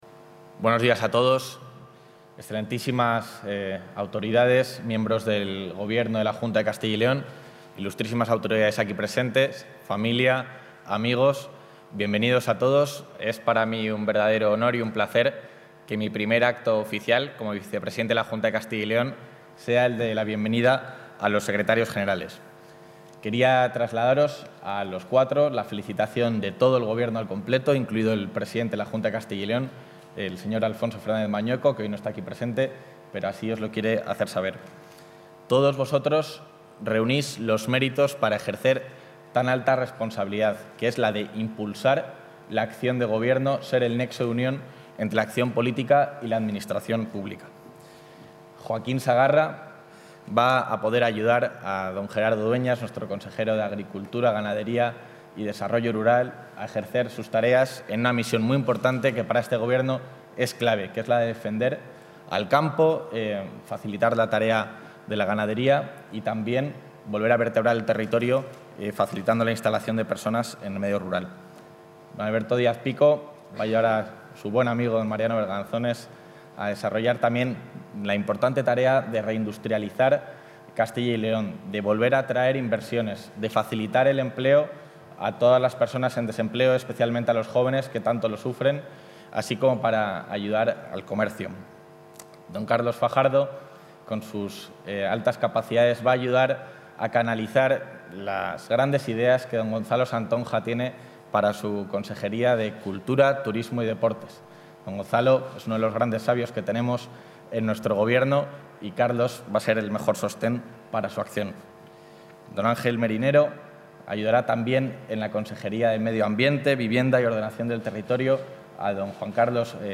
Intervención del vicepresidente.
El vicepresidente de la Junta de Castilla y León, Juan García-Gallardo, ha presidido hoy en Valladolid el acto de toma de posesión de los secretarios generales de las consejerías de Industria, Comercio y Empleo, Alberto Pico; de Medio Ambiente, Vivienda y Ordenación del Territorio, Ángel Marinero; de Agricultura, Ganadería y Desarrollo Rural, Joaquín Sagarra; y de Cultura, Turismo y Deporte, Carlos Fajardo.